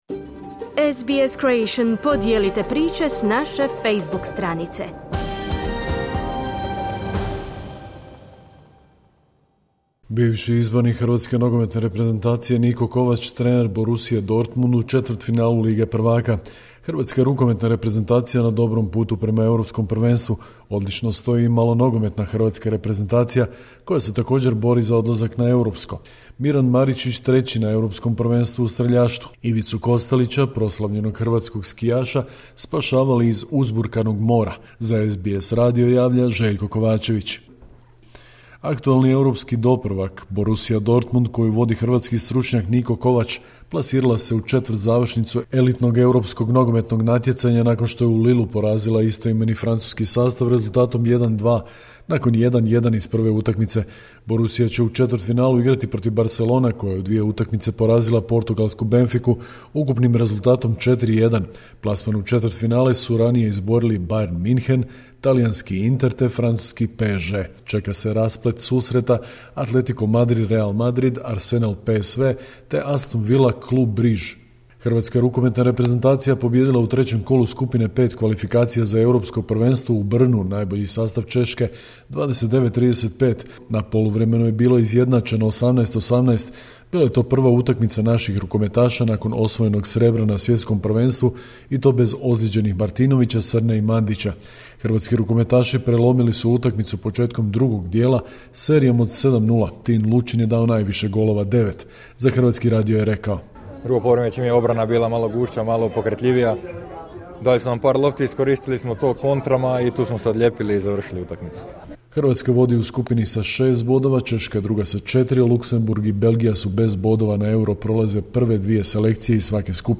Sportske vijesti, 13.3.2025.